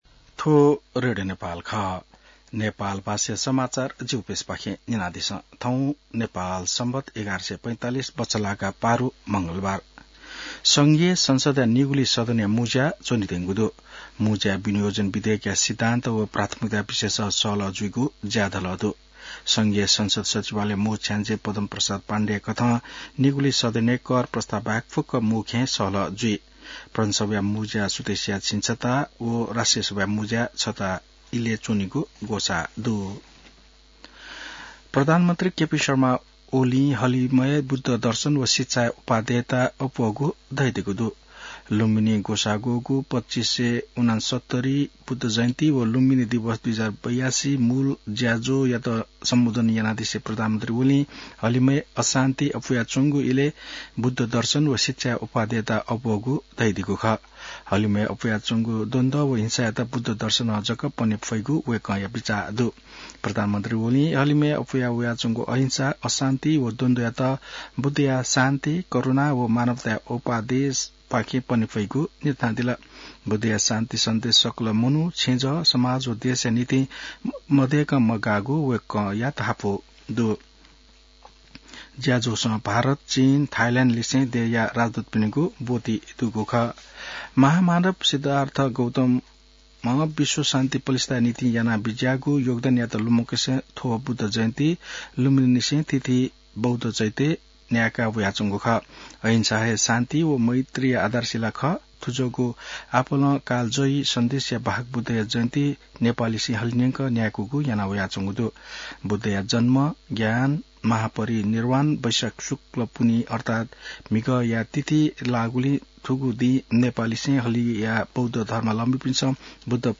नेपाल भाषामा समाचार : ३० वैशाख , २०८२